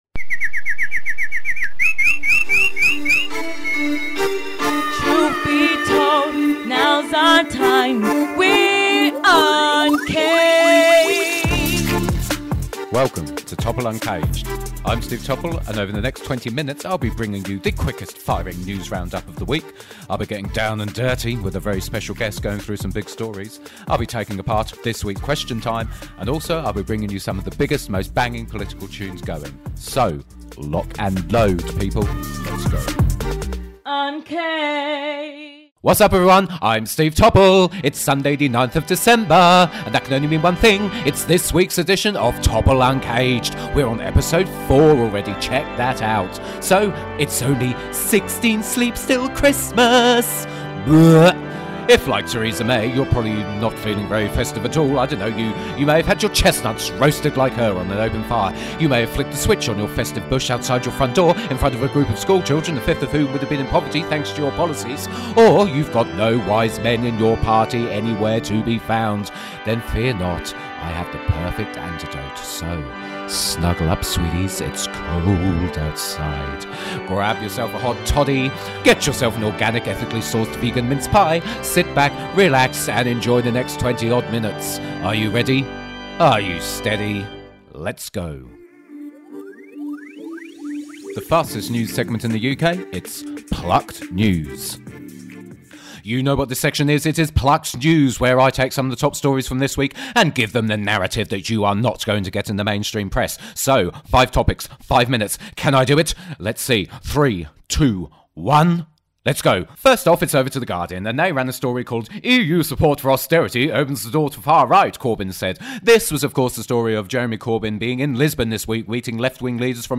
And finally, I’ll be bringing you the freshest, most banging political music in Revolutionary Bird Song.